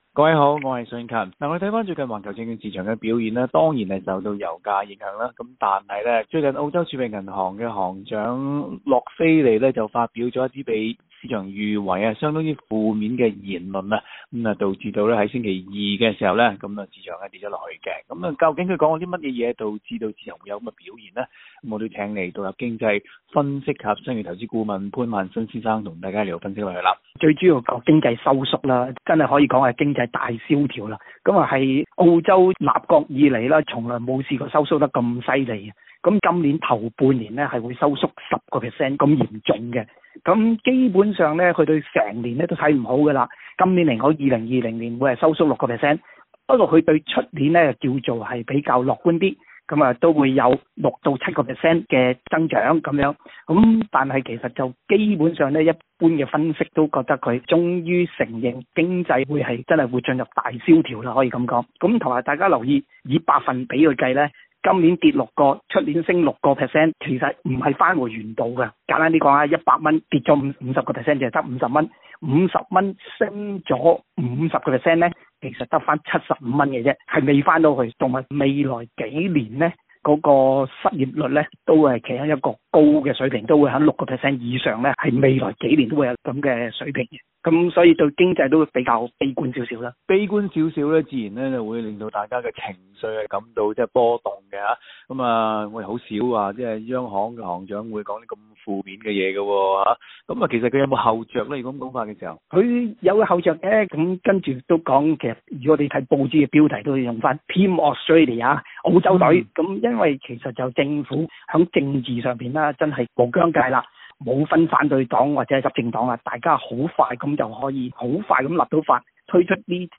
Source: AAP SBS广东话播客 View Podcast Series Follow and Subscribe Apple Podcasts YouTube Spotify Download (18.5MB) Download the SBS Audio app Available on iOS and Android 储备银行行长洛菲利发出警告澳洲头半年经济增长会报6%负增长。